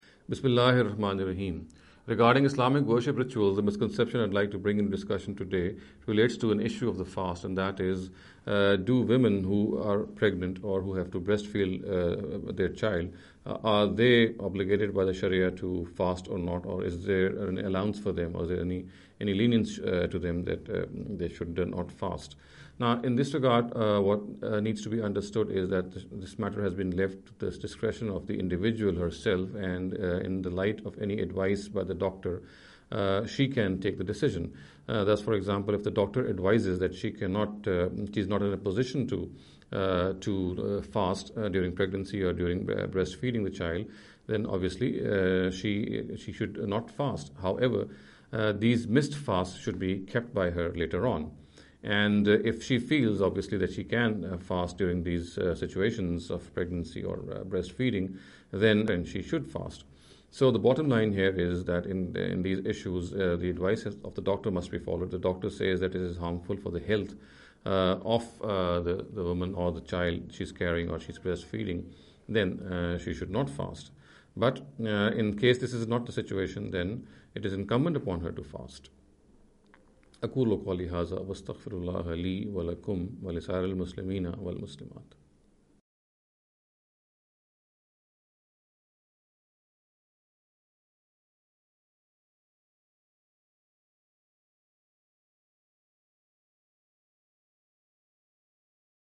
In the series of short talks “Islamic Worship Rituals